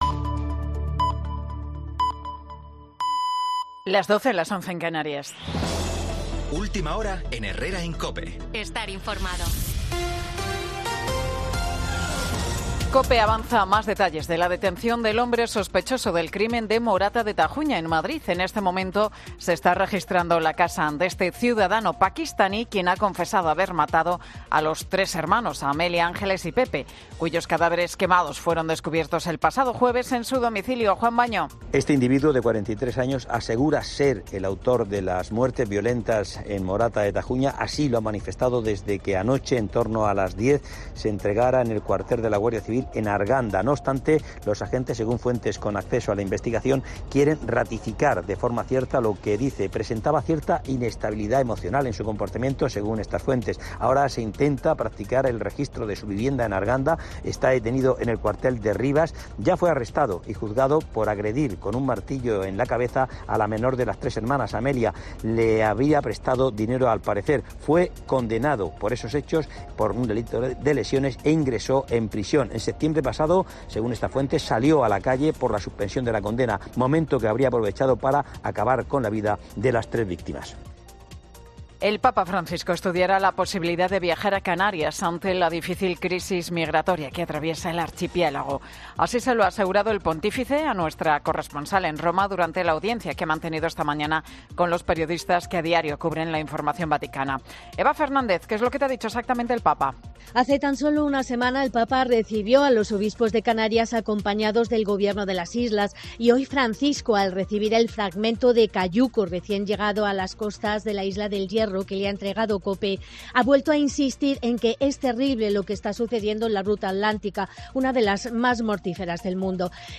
Boletín de Noticias de COPE del 22 de enero del 2024 a las 12 horas